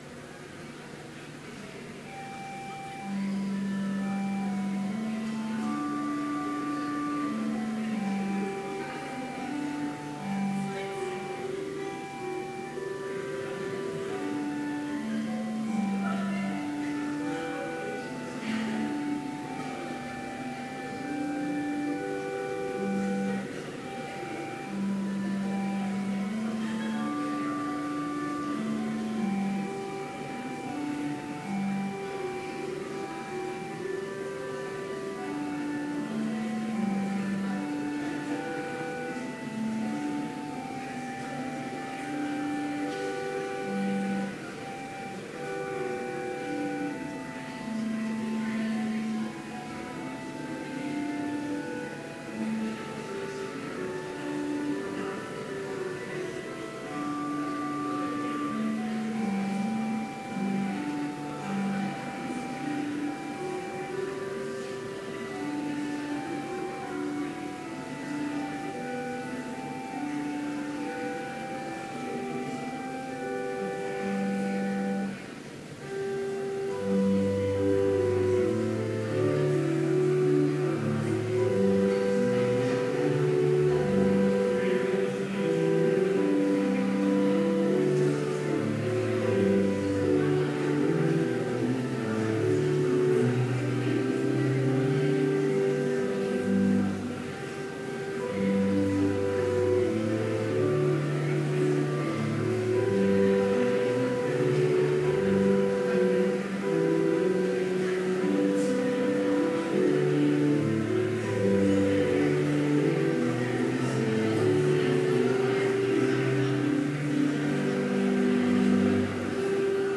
Complete service audio for Chapel - October 9, 2017